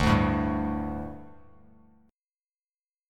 Dsus4 chord